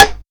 disparo.wav